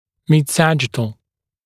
[mɪd’sæʤɪtl][мид’сэджитл]срединно-сагиттальный